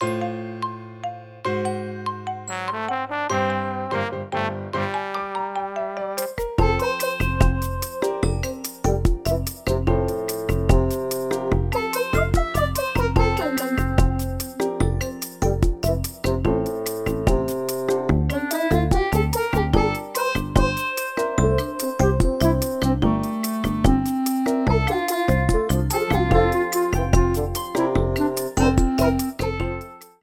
Music inspired from bossa nova
Trimmed file to 30 seconds, applied fadeout